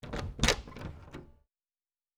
04_书店内_关门.wav